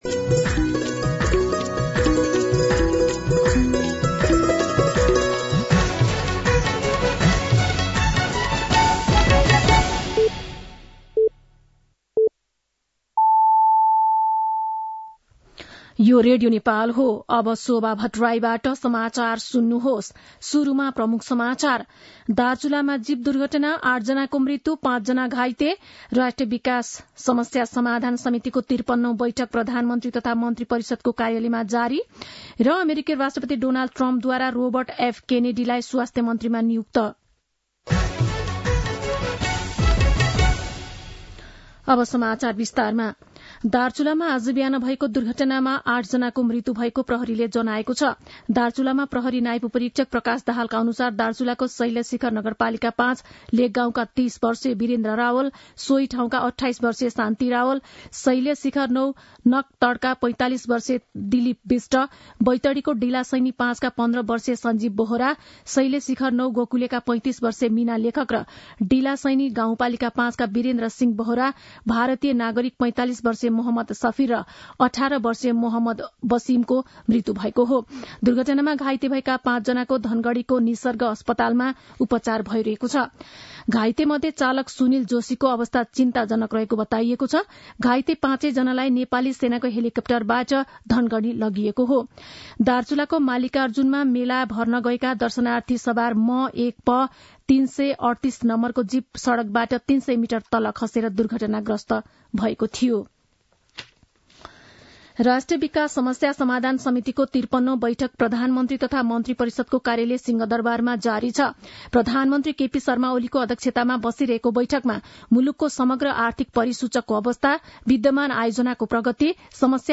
दिउँसो ३ बजेको नेपाली समाचार : १ मंसिर , २०८१
3-pm-Nepali-News-1.mp3